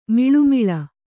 ♪ miḷumiḷa